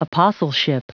Prononciation du mot apostleship en anglais (fichier audio)
Prononciation du mot : apostleship